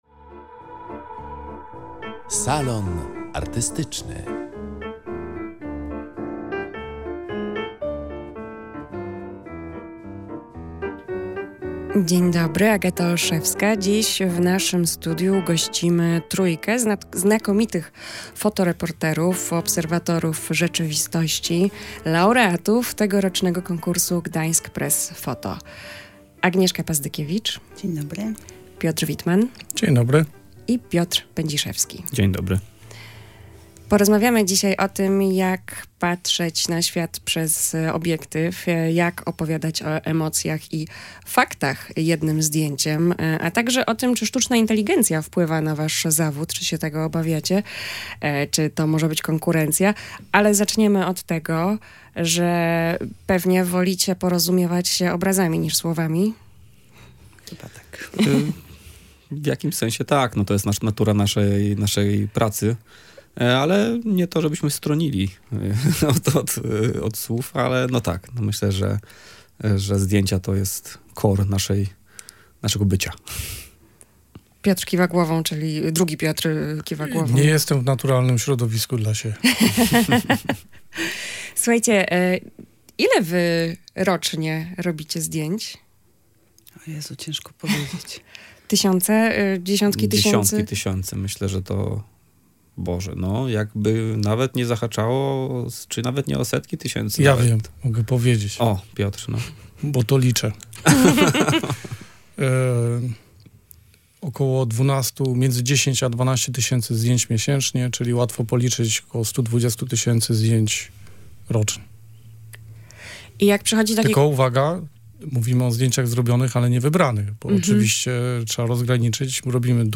Na te wszystkie pytania w „Salonie Artystycznym” odpowiadali laureaci Gdańsk Press Photo 2025.